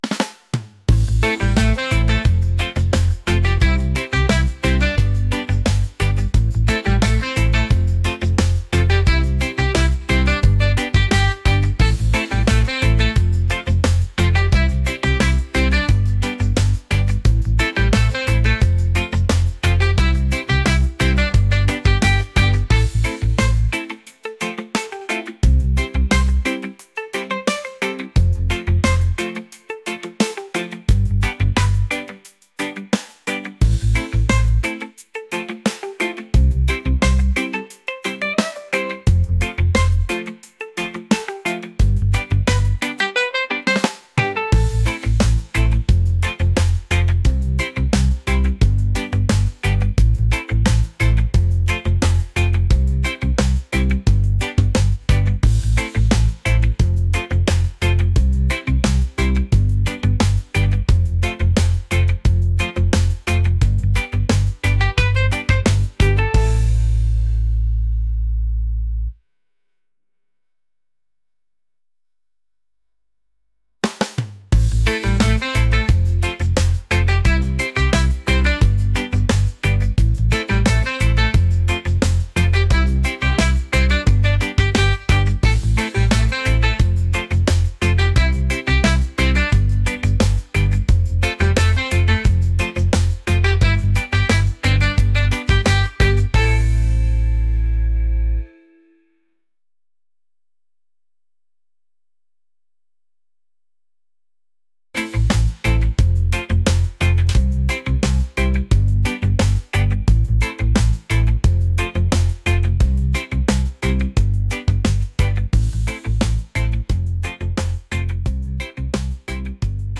upbeat | positive | reggae